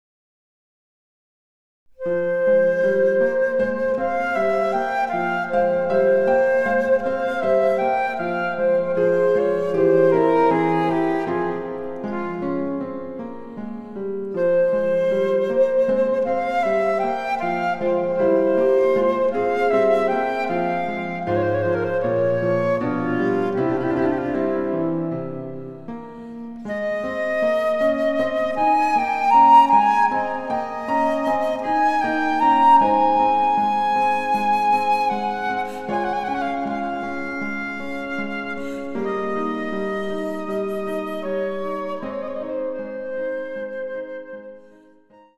★フルートの名曲をピアノ伴奏つきで演奏できる、「ピアノ伴奏ＣＤつき楽譜」です。
ソナタ　イ長調　K. V. １２